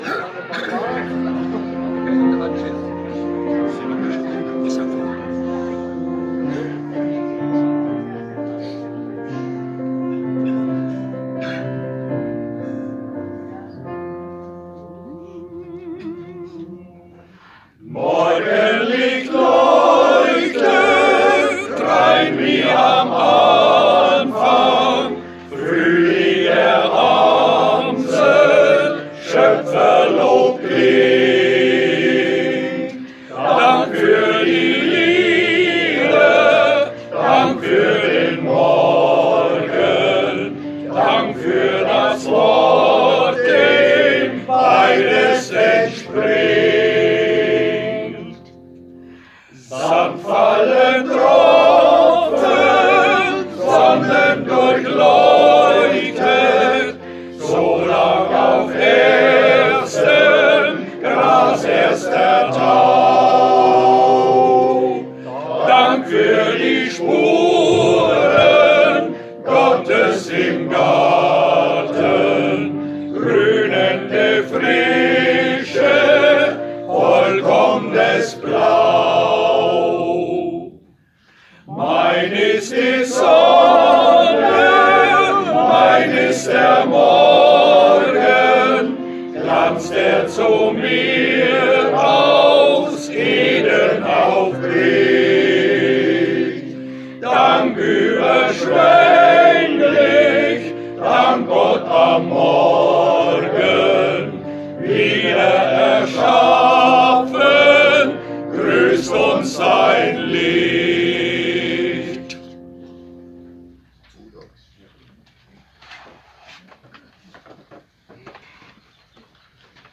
250727_GoDi_Rathausplatz_k.mp3